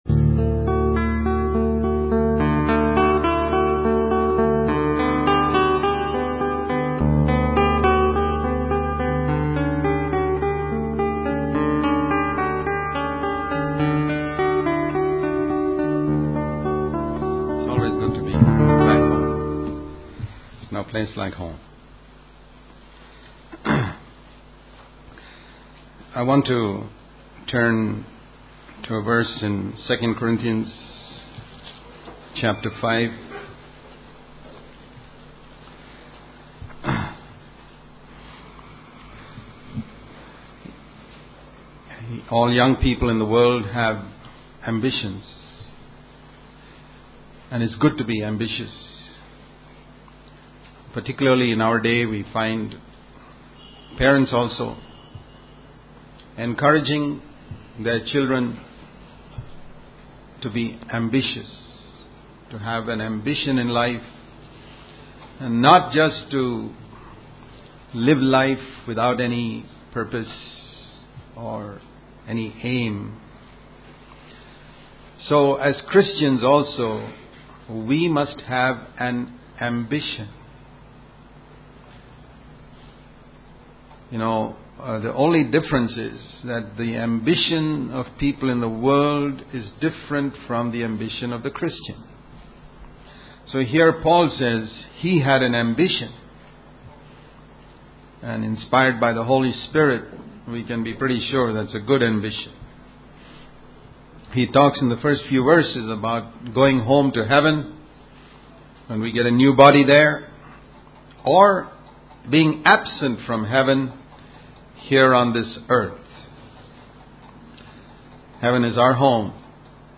This sermon emphasizes the importance of having an ambition as Christians, focusing on pleasing God whether in heaven or on earth. It highlights the need to prioritize pleasing God above all else, to be driven by one ambition of pleasing Him in heaven, and to experience the joy and excitement of living for God. The speaker encourages honesty and gratitude, urging listeners to seek God's kingdom first, do good, and share with others as sacrifices that please God.